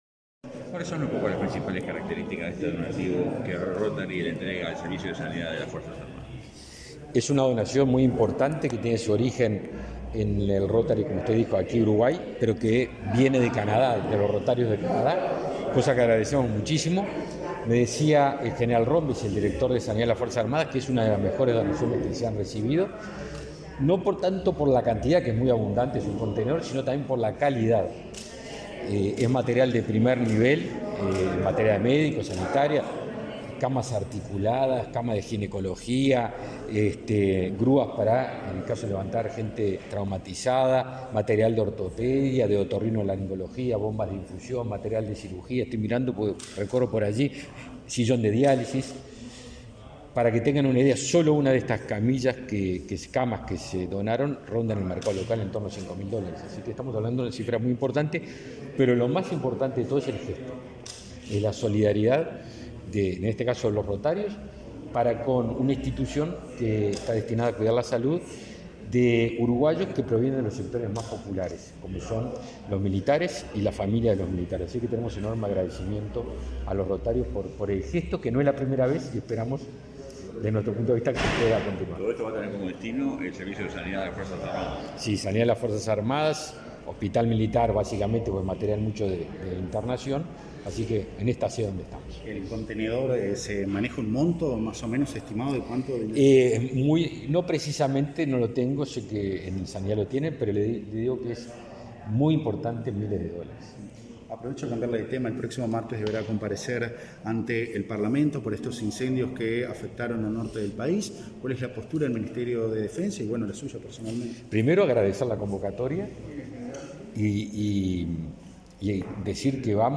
Declaraciones a la prensa del ministro de Defensa Nacional, Javier García
Tras el acto, el jerarca efectuó declaraciones a la prensa.